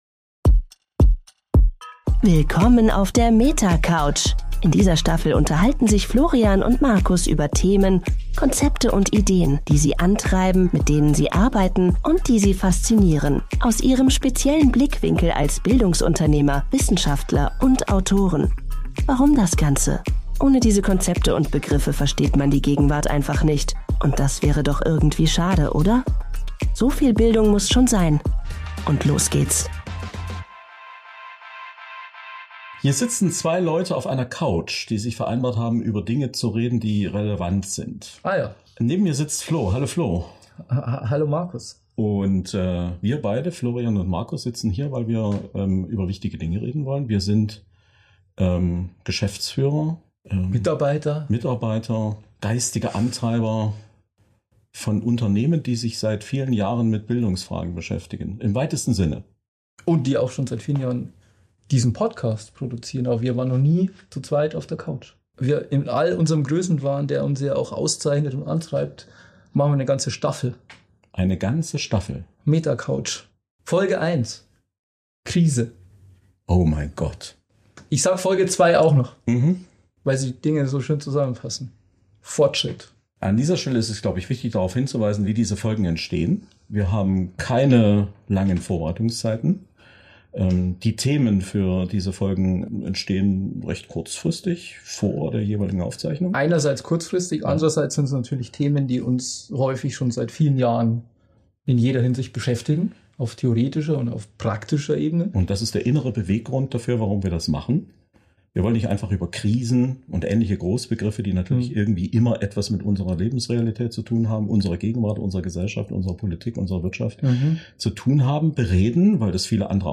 Keine Gäste, keine Floskeln, keine einfachen Antworten.